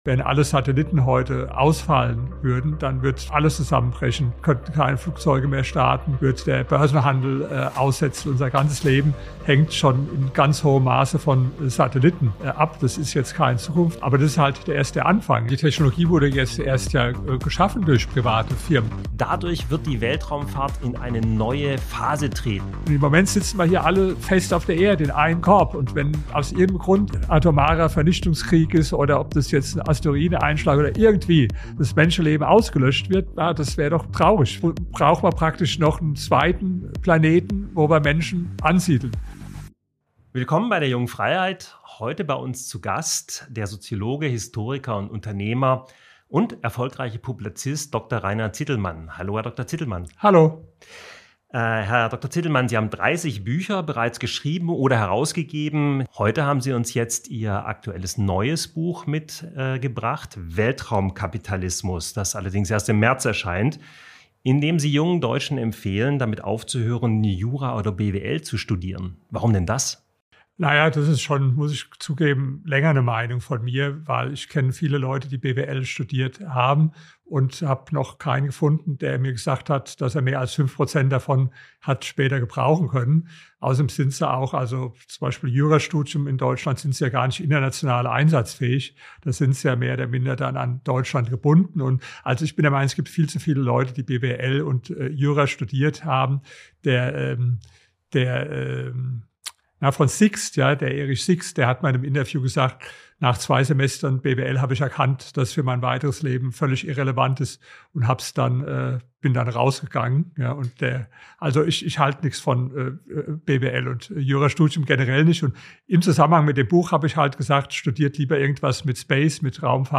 Episode #254 - Weltraumkapitalismus: Private Weltraumunternehmen erobern das All | Rainer Zitelmann im JF-Interview